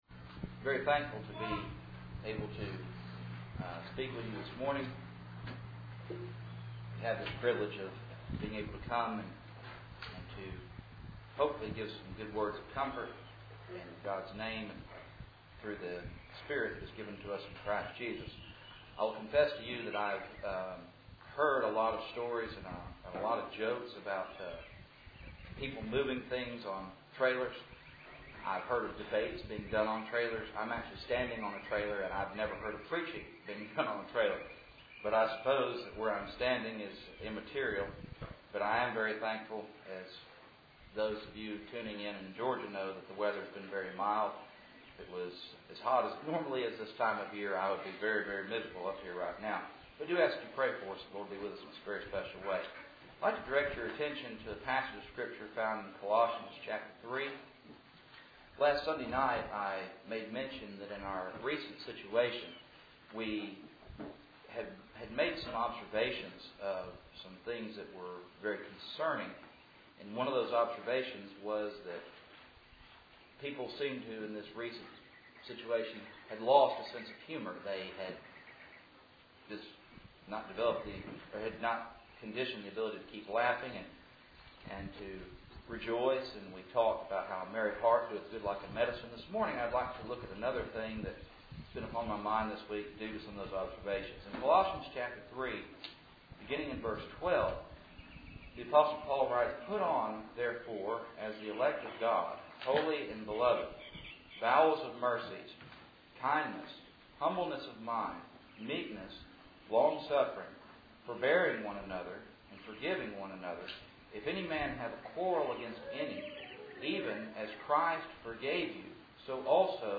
Service Type: Cool Springs PBC 1st Saturday